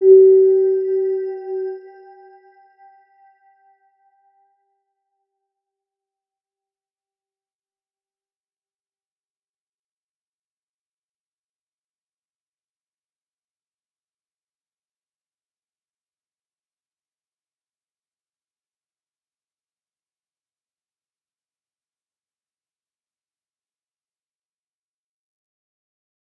Round-Bell-G4-p.wav